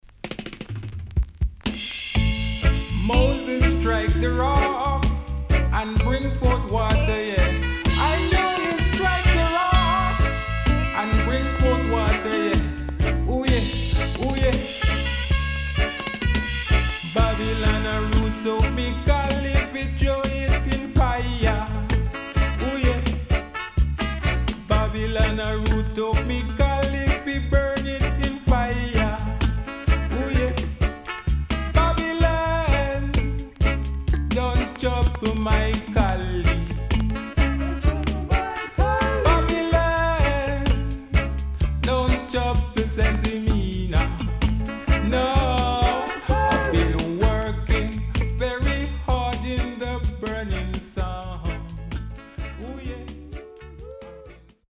Good Ganja Tune